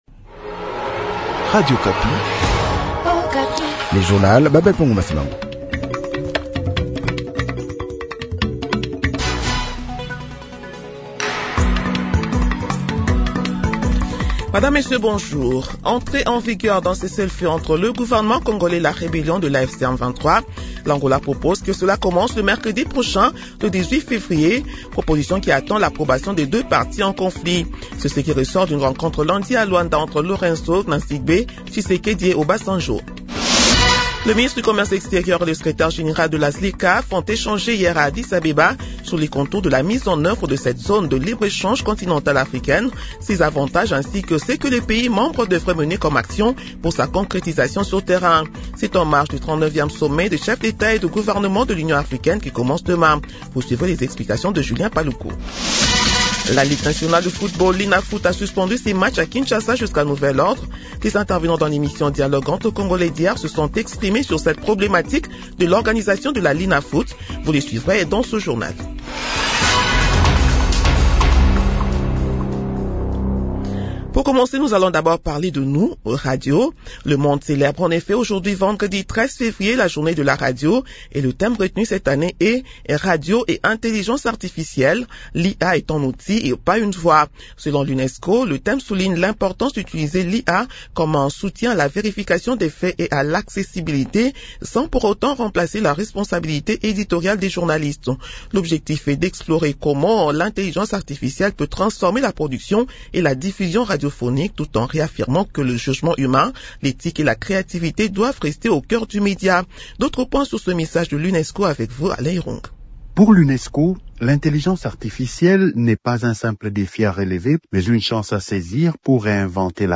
Journal matin 7 heures